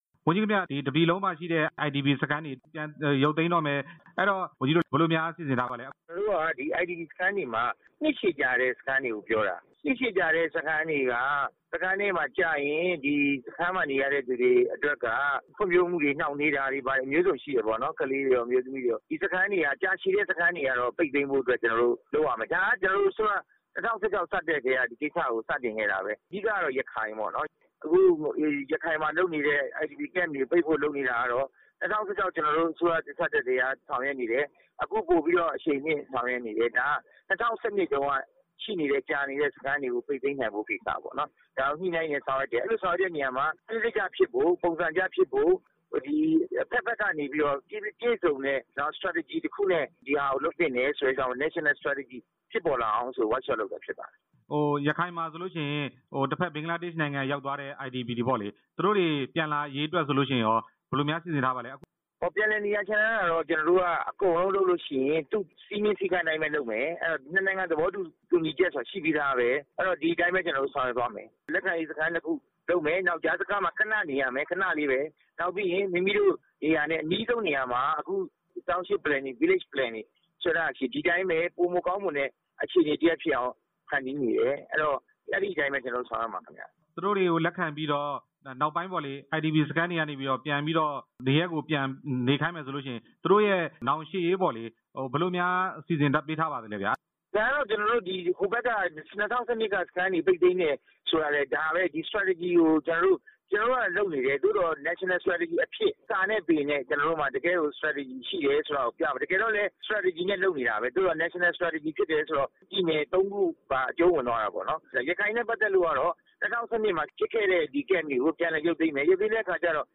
ဒုက္ခသည်စခန်းတွေ ပိတ်သိမ်းနိုင်ရေး အခြေအနေ မေးမြန်းချက်